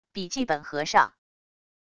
笔记本合上wav音频